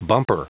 Prononciation du mot bumper en anglais (fichier audio)
Prononciation du mot : bumper